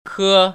[kē] 커